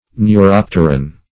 Neuropteran \Neu*rop"ter*an\ (n[-u]*r[o^]p"t[~e]r*an), n.